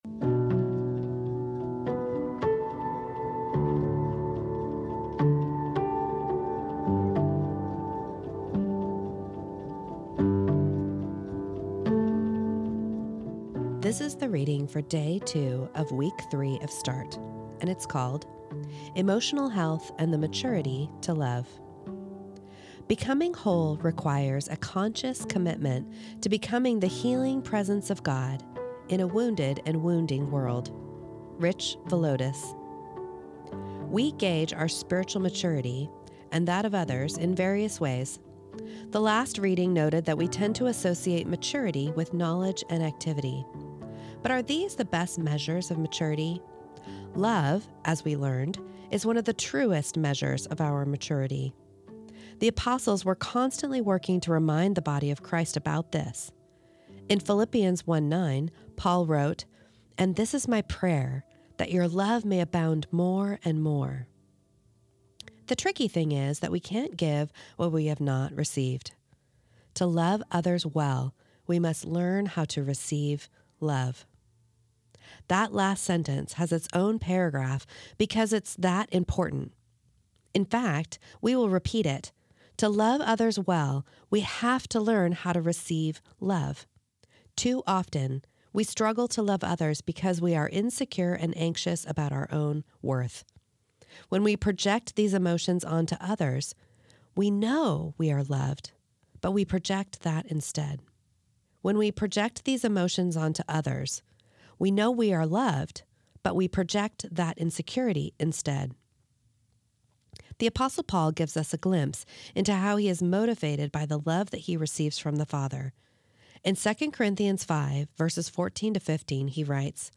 This is the audio recording of the second reading of week three of Start, entitled Emotional Health and the Maturity to Love.